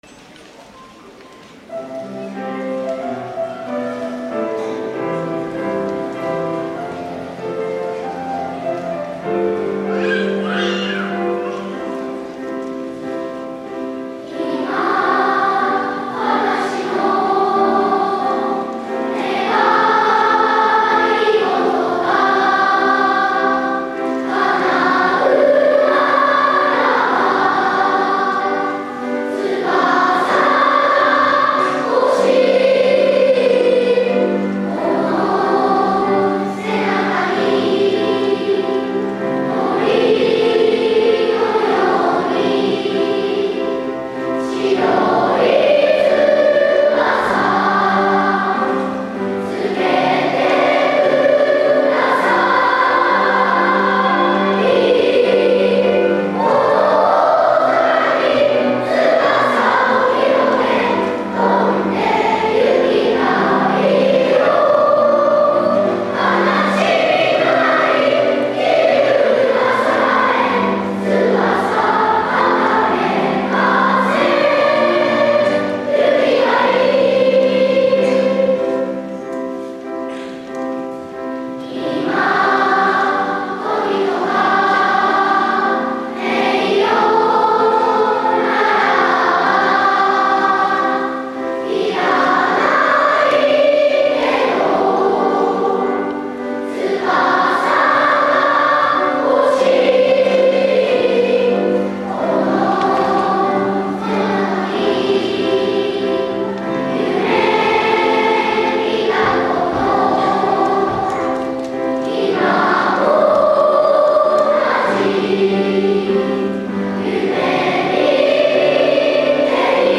会場二部合唱。
今日も会場のみなさんと歌い、みんなの心と心をつなげて笑顔いっぱいにします。